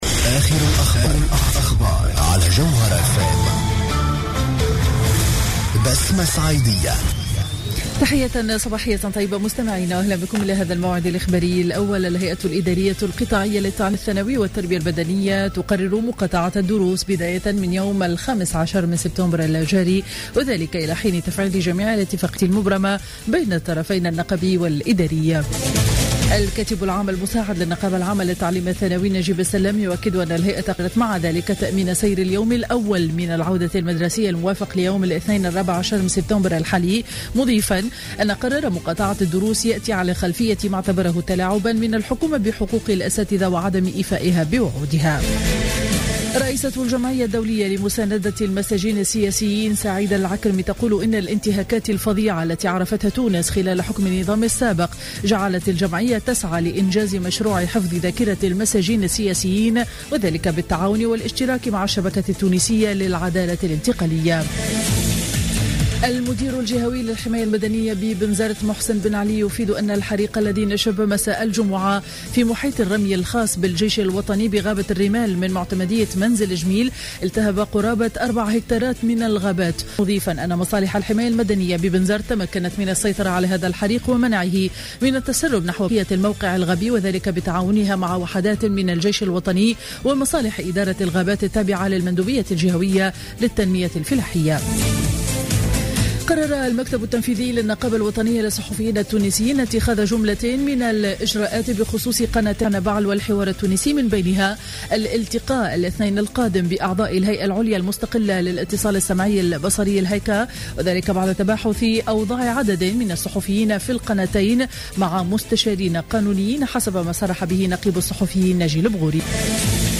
نشرة أخبار السابعة صباحا ليوم الأحد 6 سبتمبر 2015